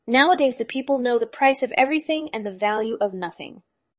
to23oise-tts - (QoL improvements for) a multi-voice TTS system trained with an emphasis on quality